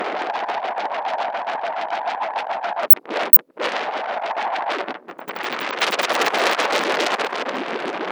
Détection au radar: